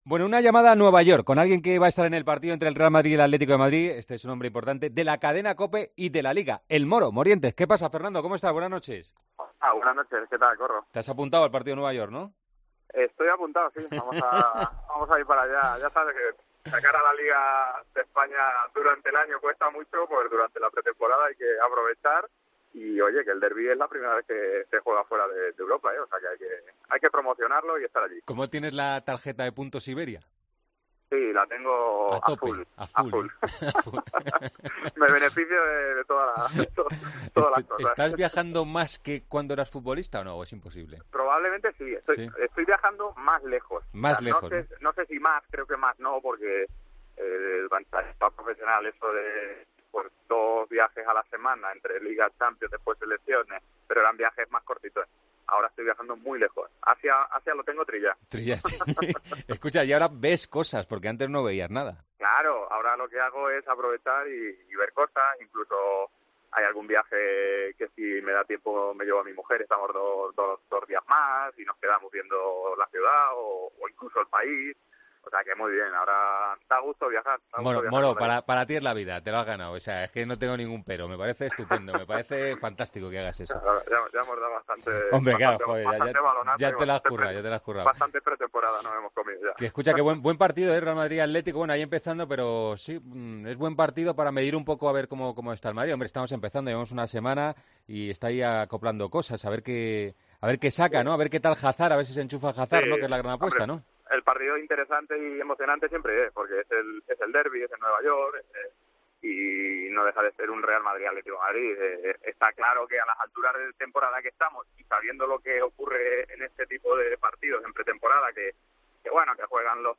Fernando Morientes ha atendido a El Partidazo de COPE desde Nueva York, donde está para vivir el derbi de la madrugada del sábado entre el Real Madrid y el Atlético de Madrid.